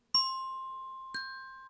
Diving_nightingale.ogg